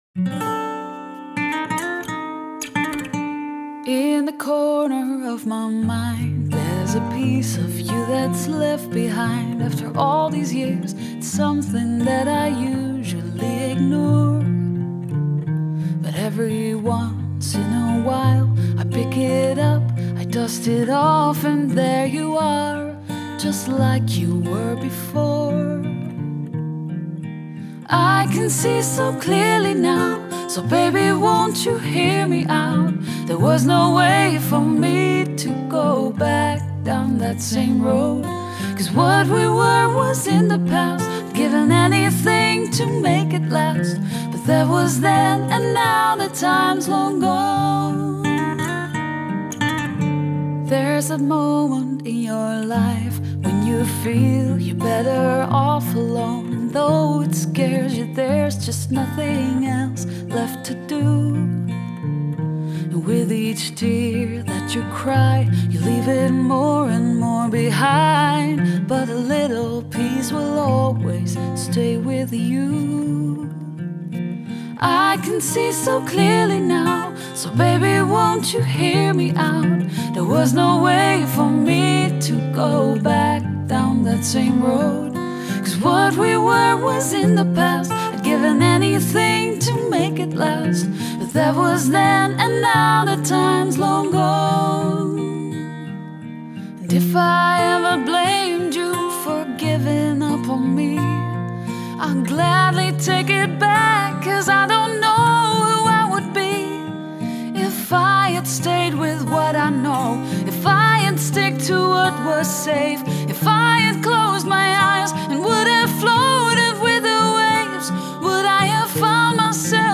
Genre: Pop, Folk Pop, Jazzy Pop